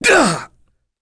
Clause-Vox_Damage_01.wav